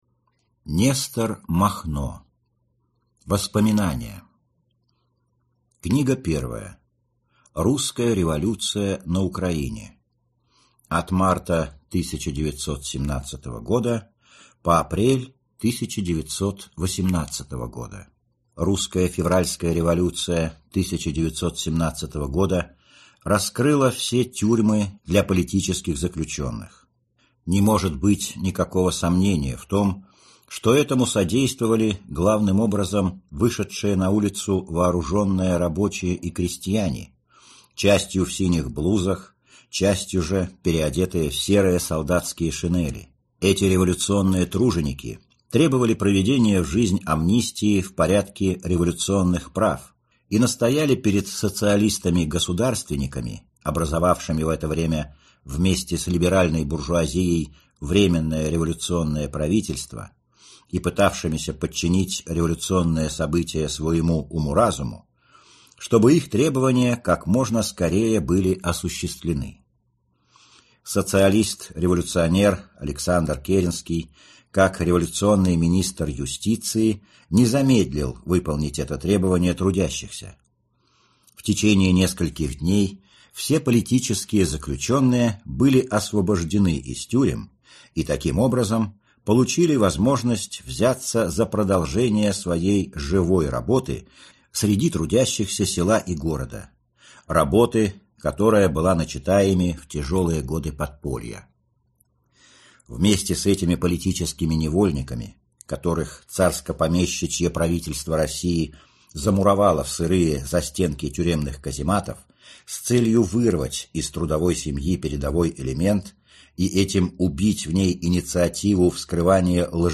Аудиокнига Воспоминания | Библиотека аудиокниг